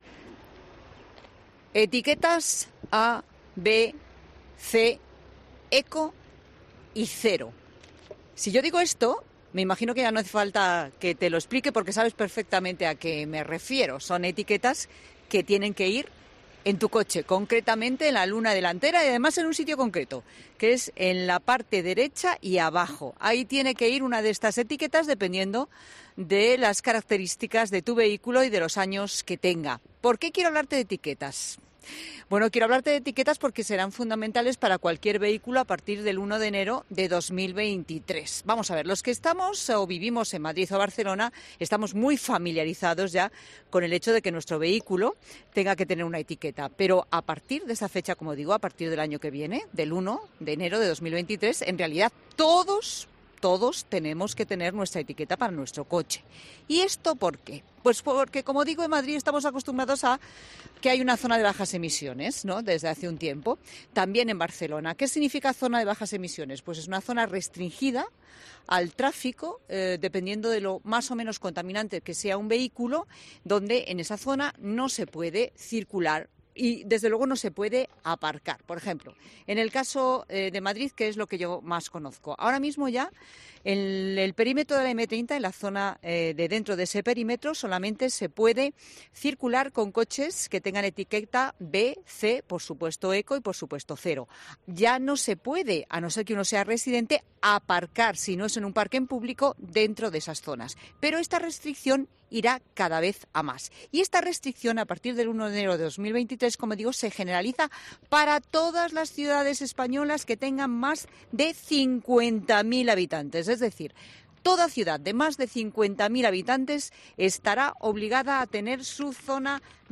Con motivo de ello, el equipo de 'La Tarde' se ha trasladado hasta el municipio madrileño de Parla, donde ya se están preparando la implantación de estas futuras Zonas de Bajas Emisiones.